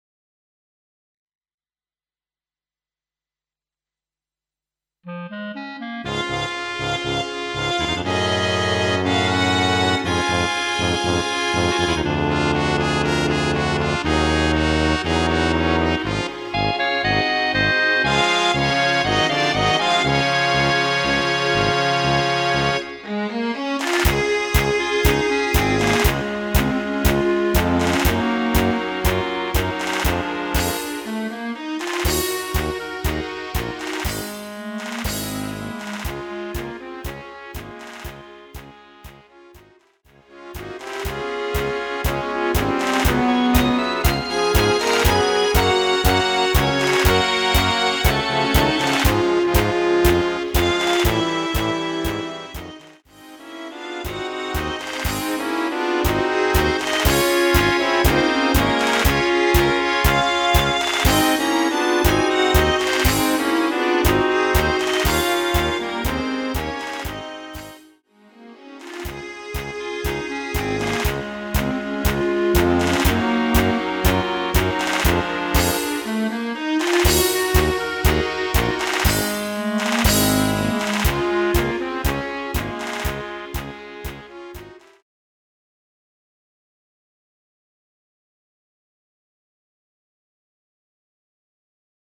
- tango